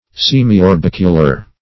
Search Result for " semiorbicular" : The Collaborative International Dictionary of English v.0.48: Semiorbicular \Sem`i*or*bic"u*lar\, a. Having the shape of a half orb or sphere.